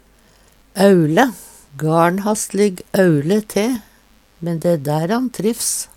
På vår dialekt har auLe med tjukk L anna tyding enn aule med tynn l.
aule.mp3